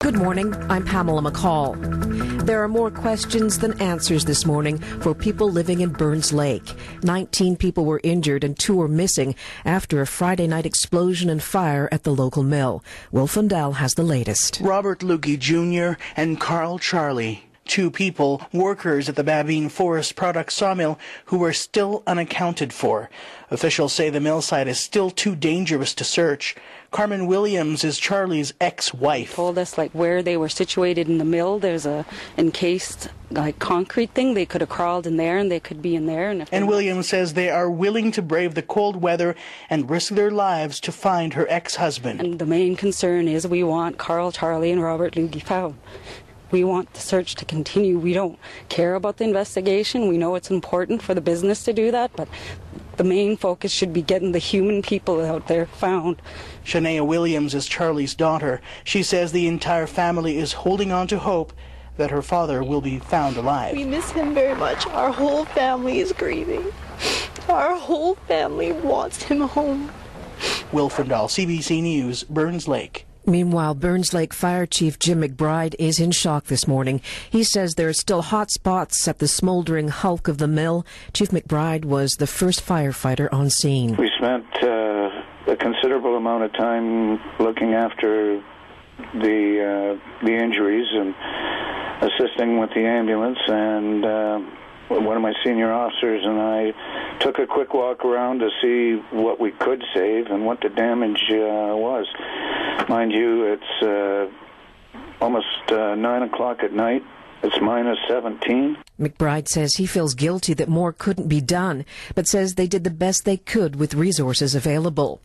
We dug up a clip from our newscast following the explosion.